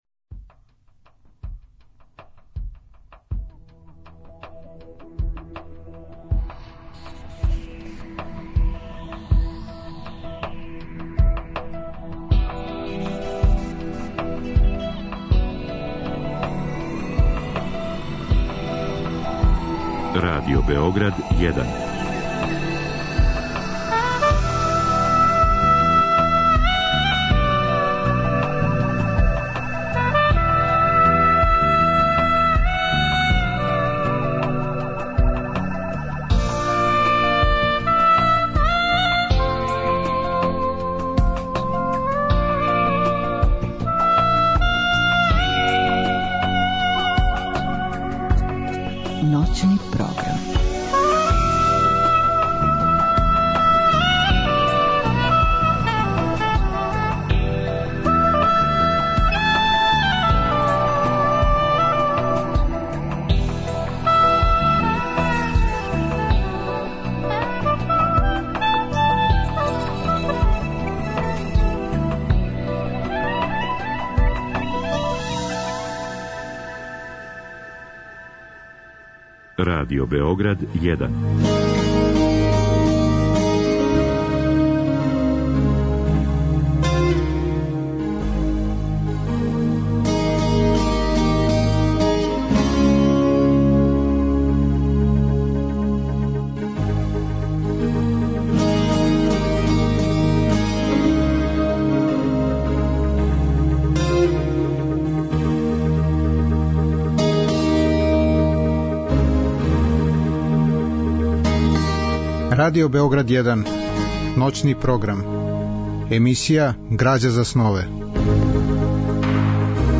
Емисија ноћног програма под називом ГРАЂА ЗА СНОВЕ бави се питањима од значаја за уметност и стваралаштво. Гости су људи из различитих професија, они који су и сами ствараоци, и блиска им је сфера духа и естетике. Разговор и добра музика требало би да кроз ову емисију и сами постану грађа за снове.